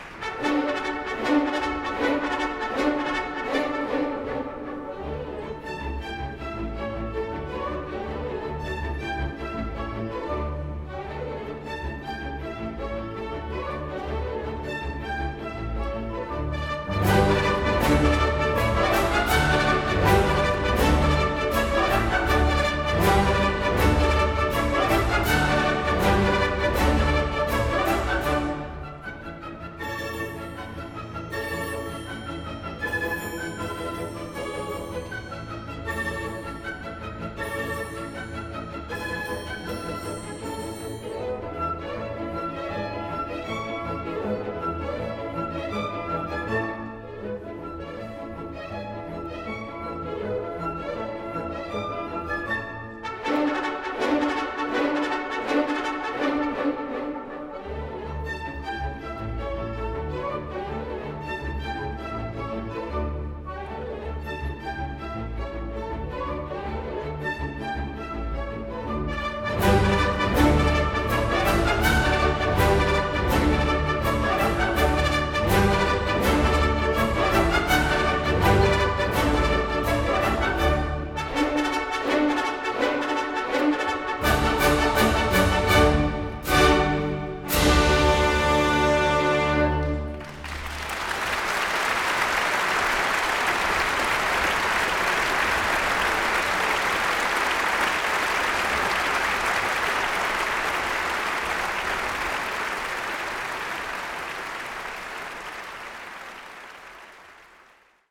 老约翰施特劳斯《入口加洛普》 本年新年音乐会的亮点，梅塔指挥。